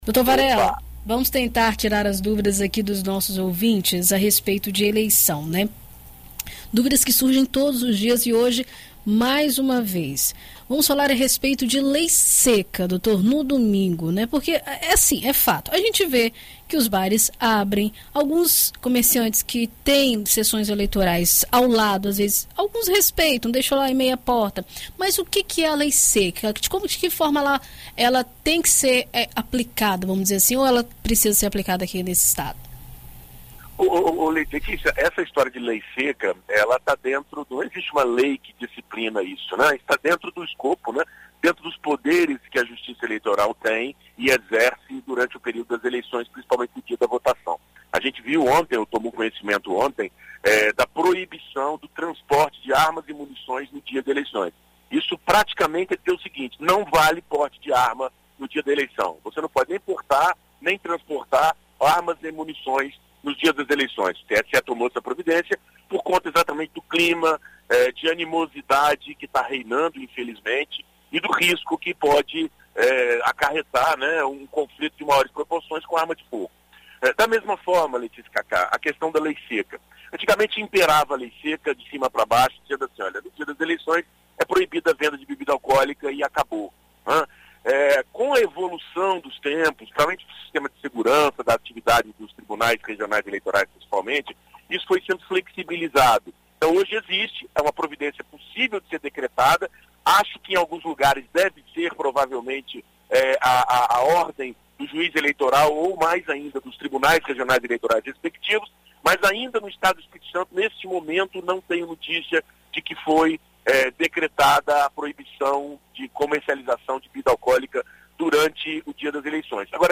Na coluna Direito para Todos desta sexta-feira (30), na BandNews FM Espírito Santo,